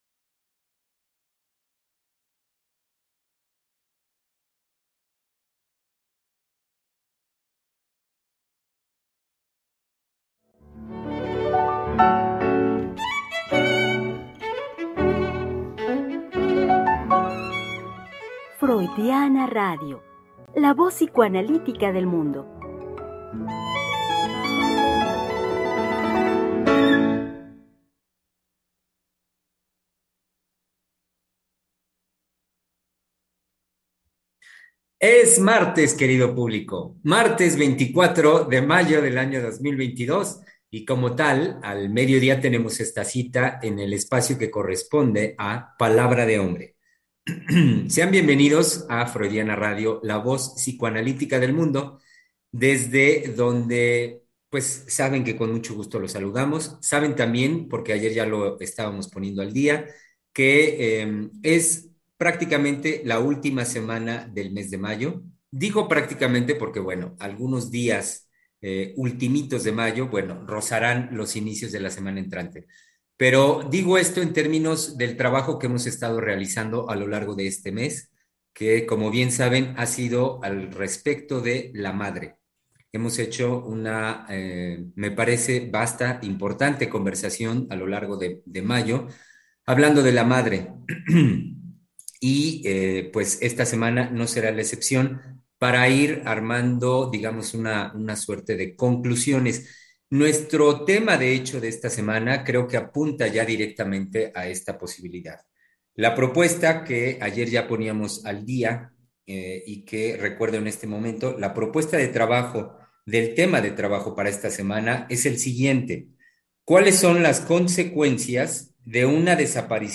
Programa transmitido el 24 de mayo del 2022.